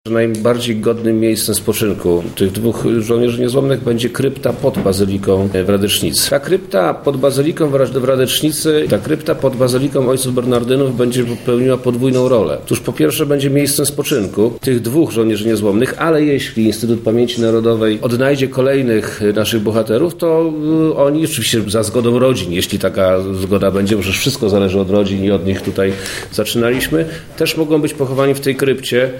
Szczątki obu żołnierzy zostaną złożone w kryptach pod Bazyliką pod wezwaniem św. Antoniego Padewskiego w Radecznicy. O powodach tej decyzji mówi wojewoda lubelski Przemysław Czarnek